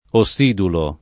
ossidulo [ o SS& dulo ]